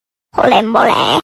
Holy Moly Meme Sound Effect Free Download